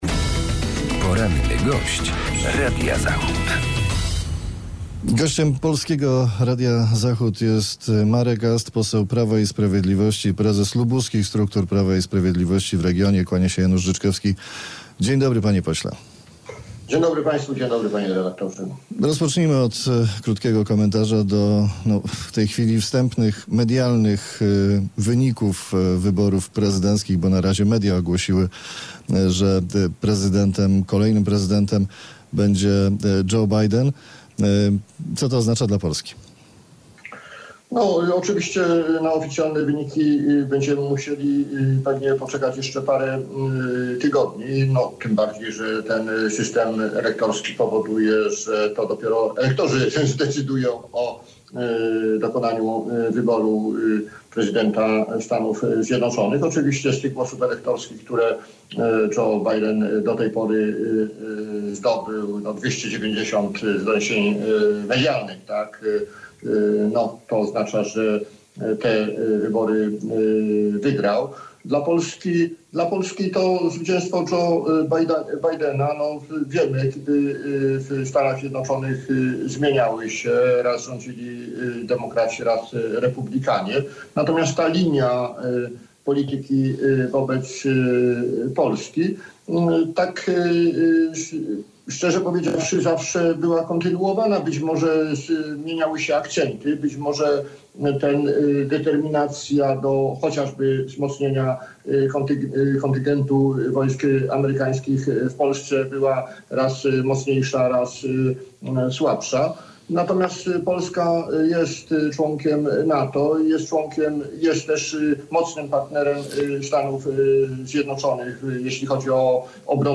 Z posłem PiS, prezesem partii w województwie lubuskim rozmawia